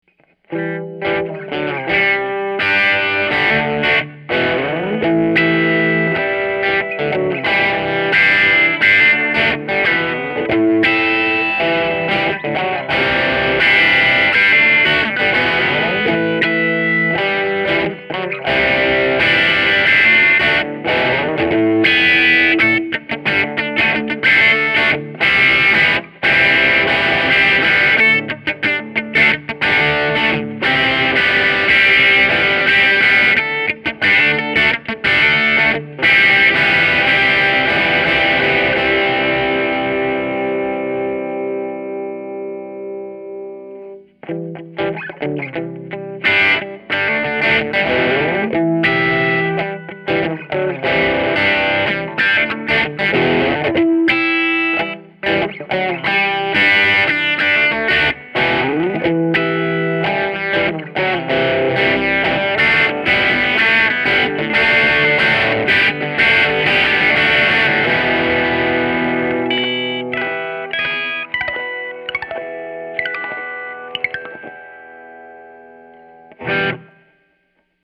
Here are 12 quick, 1-take MP3 sound files of myself playing this guitar to give you an idea of what to expect. The guitar has great tone, sustain, and easy playability, and also excellent tonal variety. The guitar is miced using a vintage Neuman U87 mic on a Peavey Studio Pro 112 amp, straight into a Sony PCM D1 flash recorder, and MP3s were made in Logic.
SGSupreme87D1Harmonic.mp3